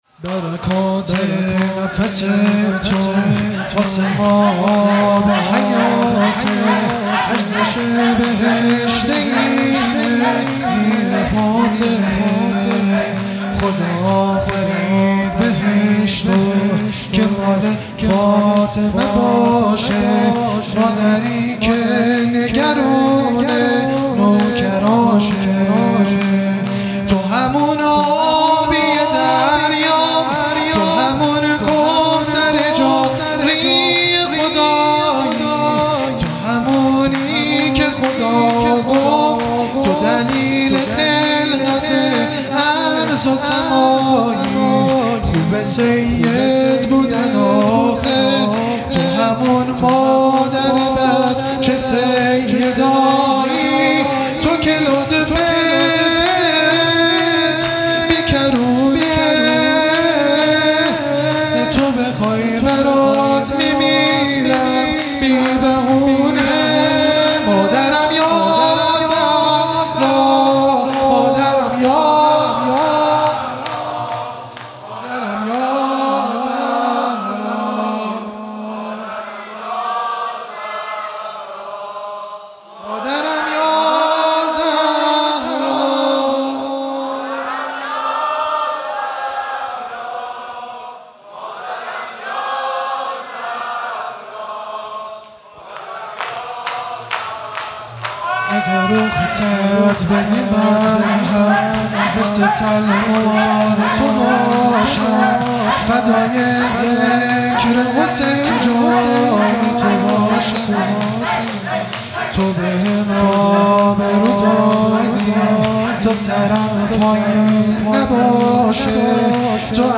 شور | برکات نفس تو واسه ما آب حیاته